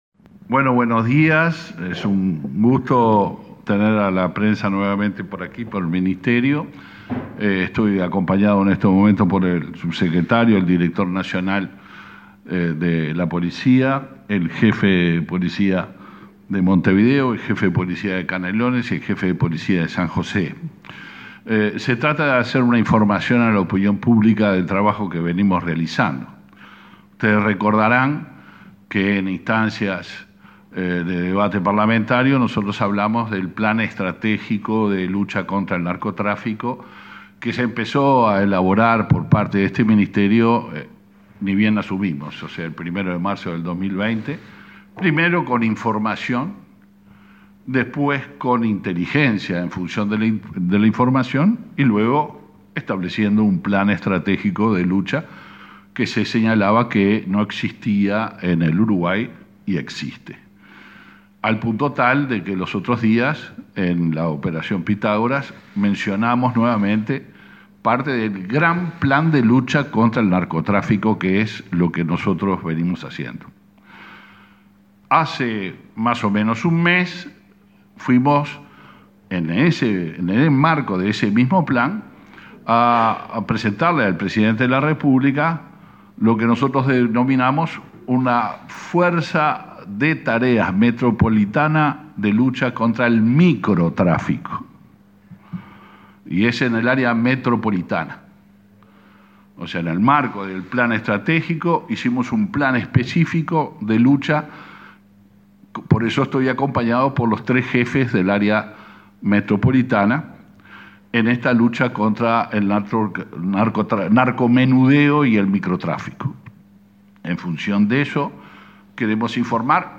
Conferencia de autoridades del Ministerio del Interior
Conferencia de autoridades del Ministerio del Interior 16/06/2022 Compartir Facebook X Copiar enlace WhatsApp LinkedIn El ministro del Interior, Luis Alberto Heber, y el jefe de Policía de Montevideo, Mario D'Elía, presentaron datos del primer mes de implementación del plan de seguridad para el Área Metropolitana.